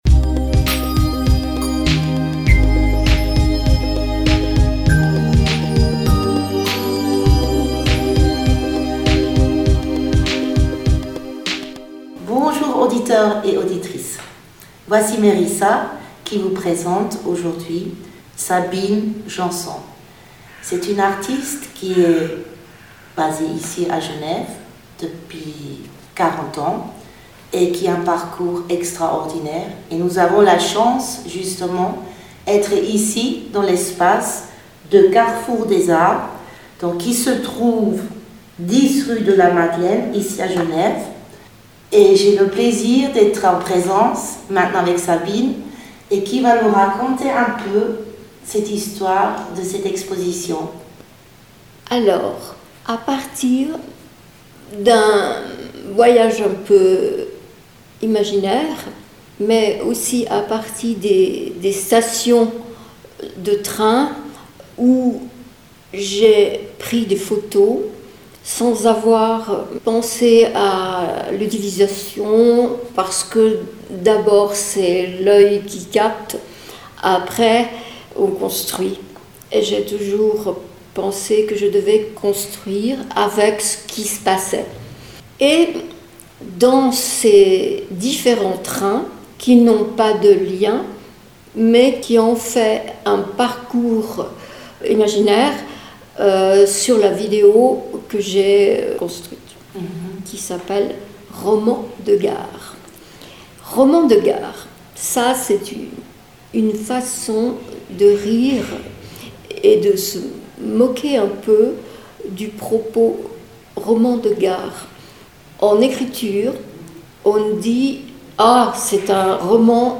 Interview
Nous vous réservons des interviews avec des invité.e.s de l’univers de la musique et de l’événementiel.